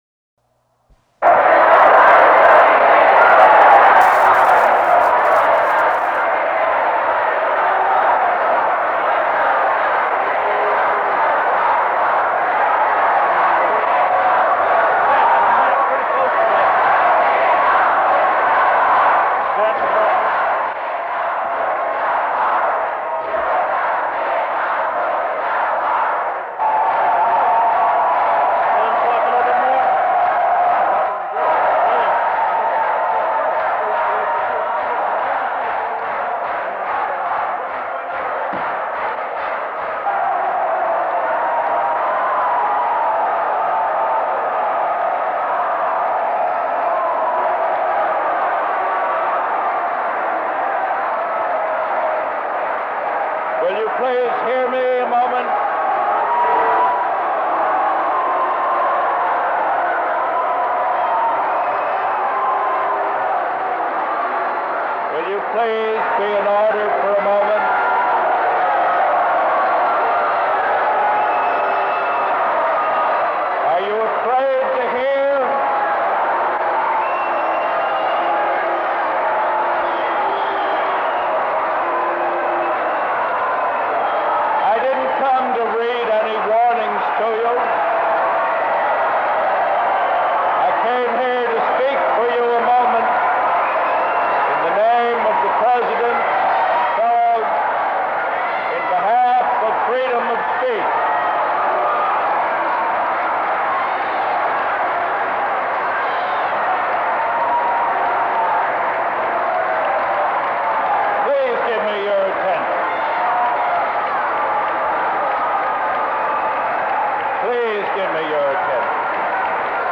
Opponents of the war disrupted the event.
The speakers never had a chance. A wall of noise shouted down the three who tried to address the audience: the moderator, a university representative, and the first speaker.
1. For further documentation: (a) a 41-minute audio recording of the Counter Teach-In, here ; (b) Harvard's three-minute film of the event, here ; (c) written documentation including press clippings, university statements, flyers, letters, here ; and (d) my next-day blow-by-blow account of the event, here .